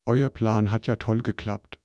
sample04-ForwardTacotron-HifiGAN.wav.wav